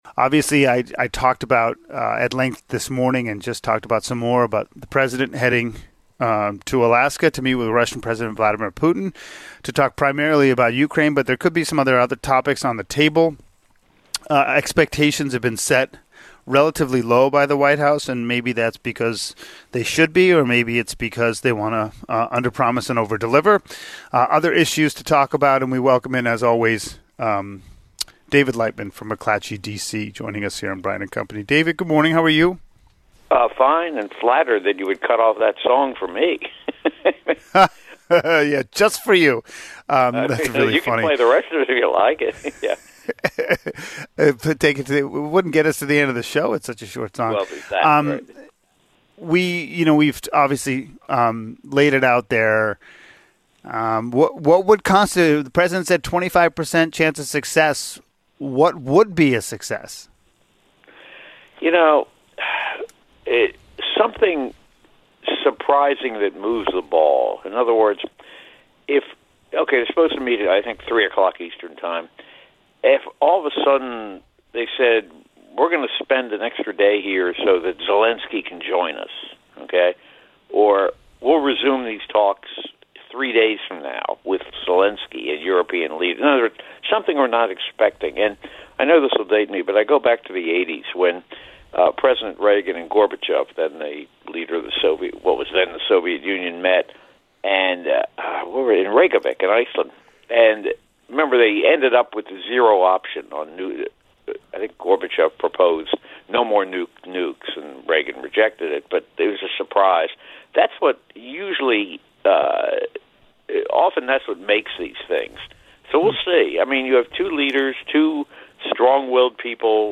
News, weather, traffic and sports plus, interviews with the people making the news each day.